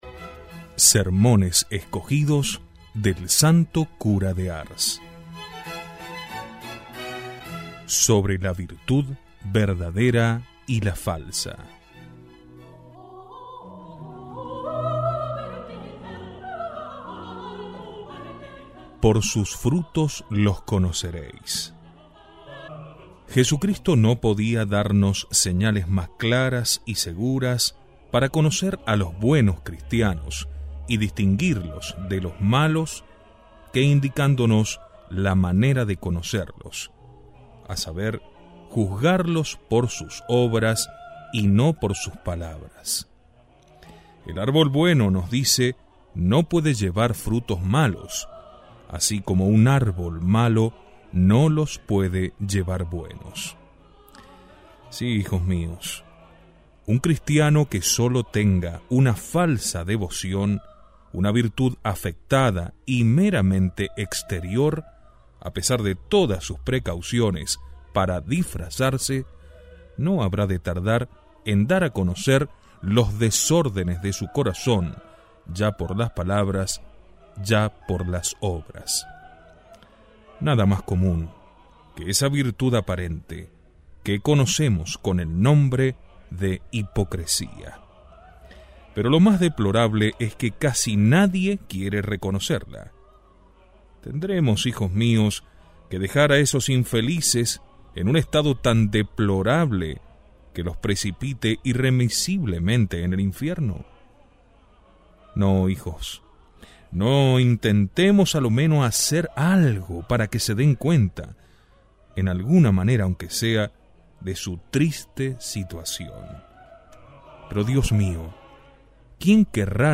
Audio–libro